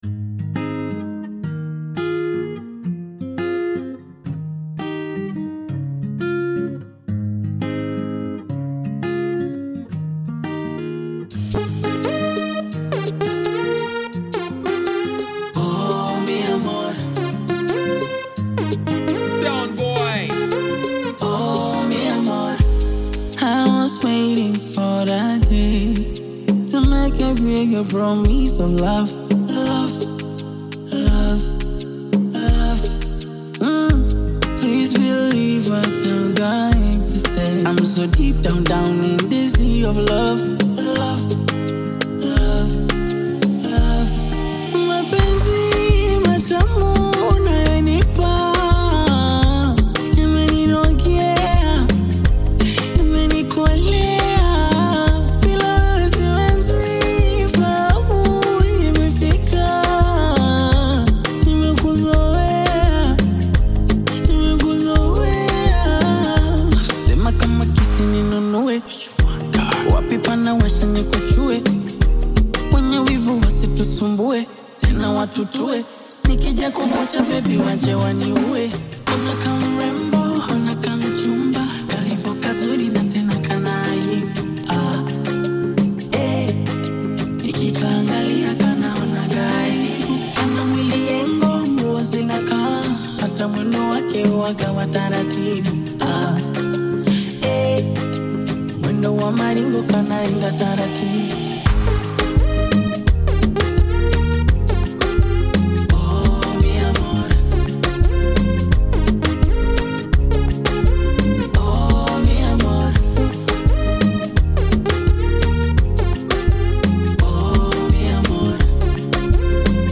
soulful music audio track